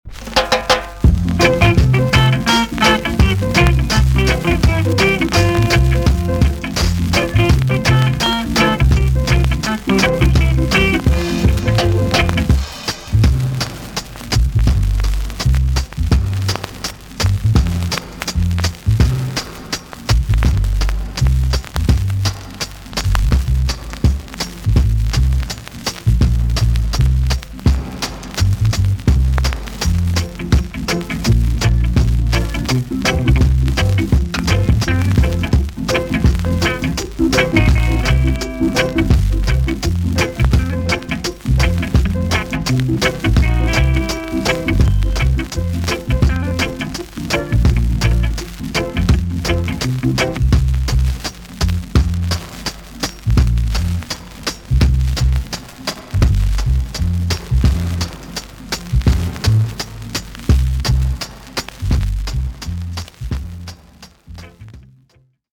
TOP >REGGAE & ROOTS
VG ok 軽いチリノイズが入ります。
CALYPSO ROOTS TUNE!!